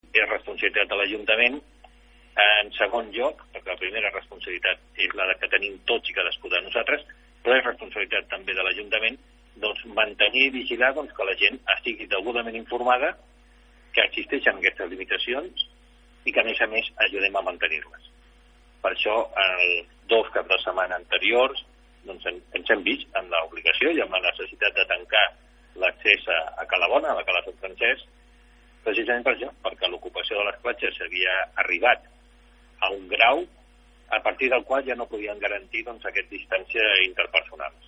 Àngel Canosa, alcalde de Blanes, ha explicat en declaracions a aquesta emissora que la decisió s’ha pres per la responsabilitat que té el consistori davant d’una ocupació de la platja que no permetia garantir la distància de seguretat entre els banyistes.